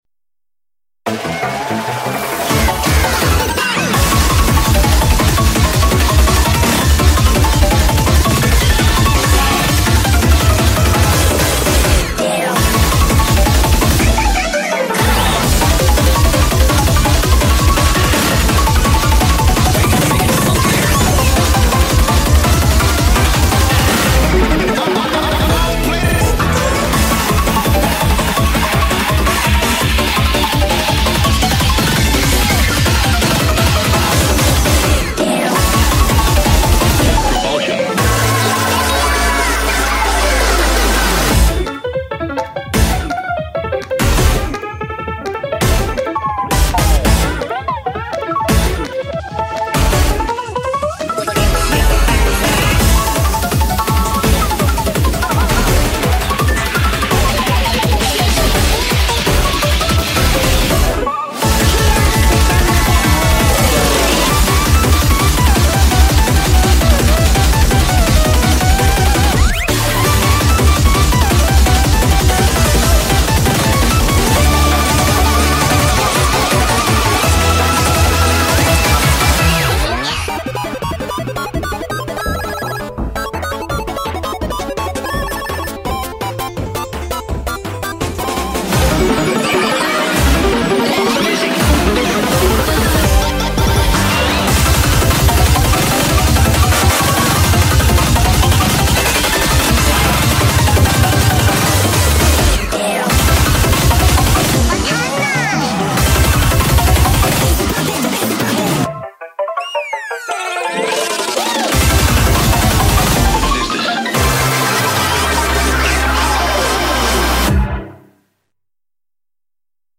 BPM167-334
Audio QualityPerfect (Low Quality)